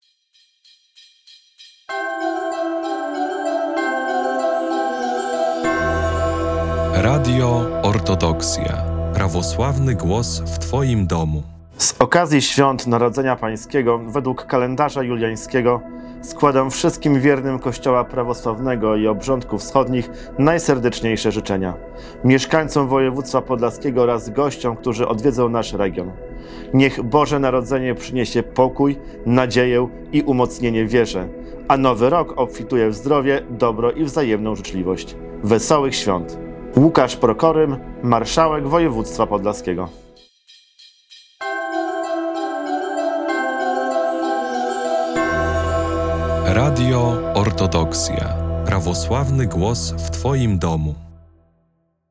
Marszałek Województwa Podlaskiego Łukasz Prokorym złożył życzenia prawosławnej społeczności obchodzącej święto Narodzenia Jezusa Chrystusa według kalendarza juliańskiego